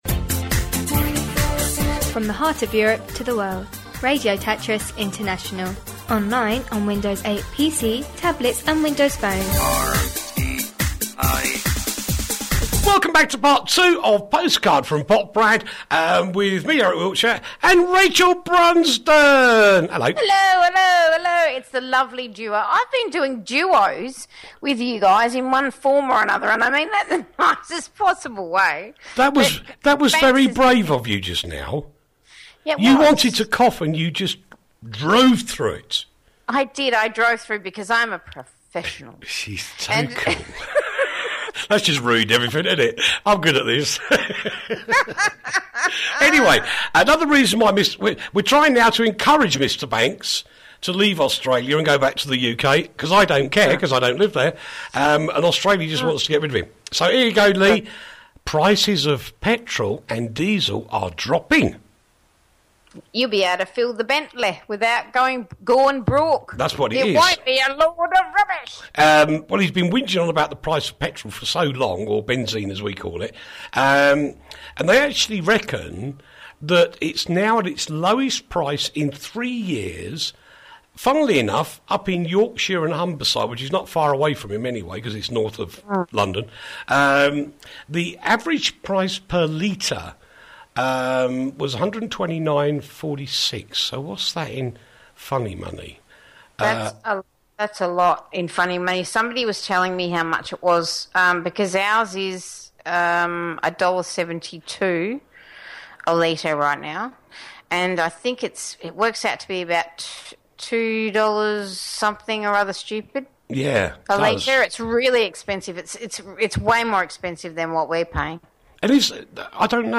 Postcard From Poprad the alternative news show from Radio Tatras International